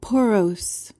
PRONUNCIATION: (por-OHS) MEANING: adjective: Having pores.